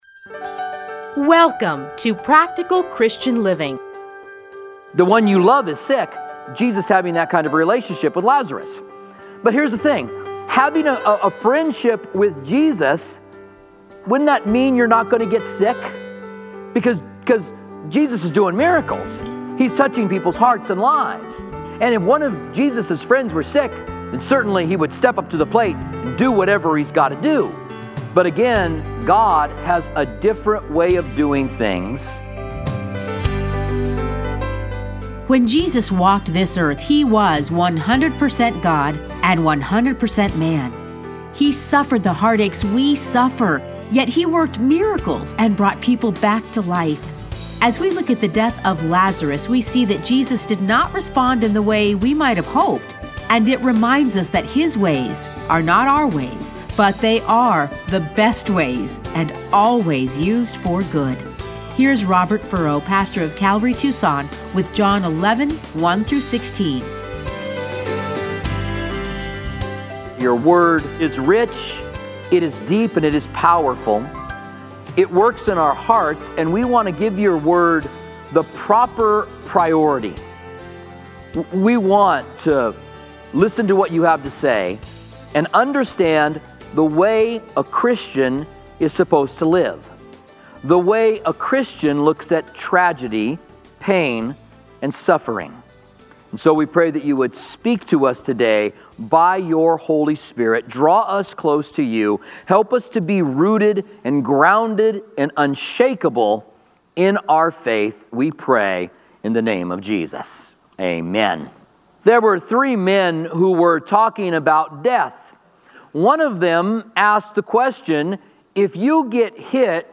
Listen to a teaching from John 11:1-16.